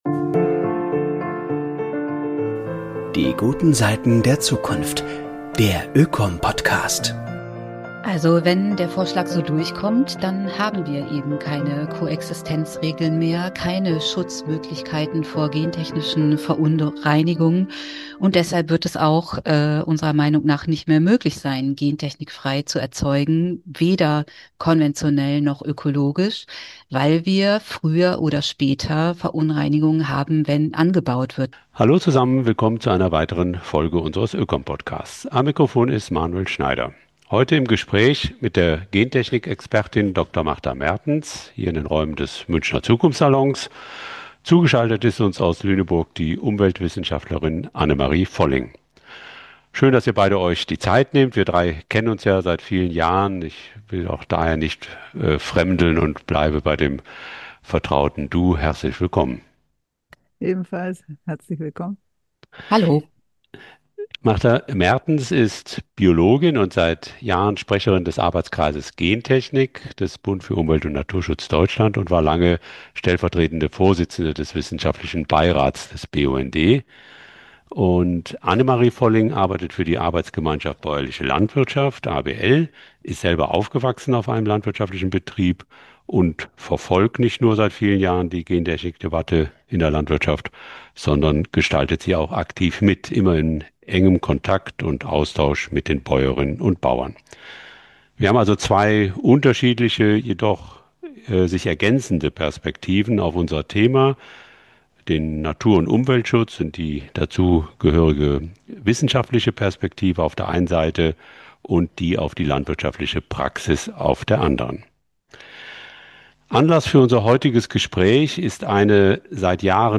Neue Gentechnik-Regeln – Gezielter Verstoß gegen Vorsorgeprinzip und Wahlfreiheit? [Ein Gespräch